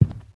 ghost-step1.mp3